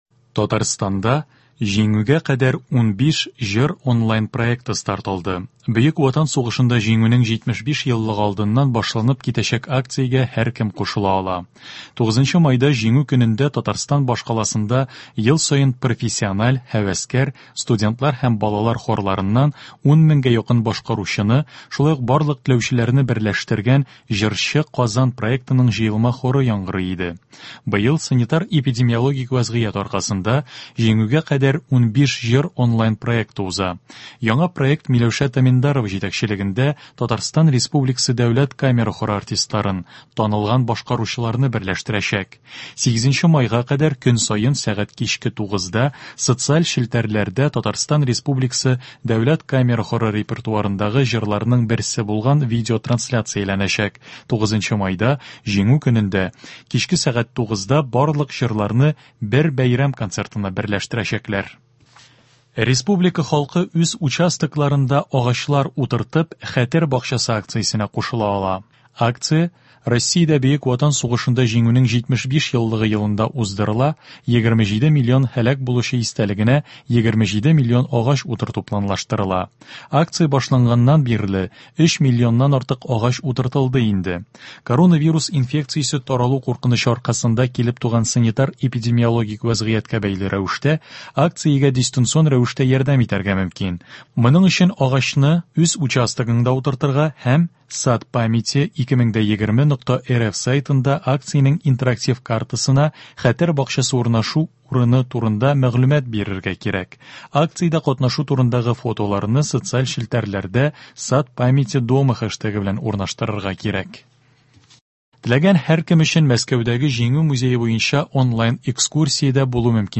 Яңалыклар. 27 апрель.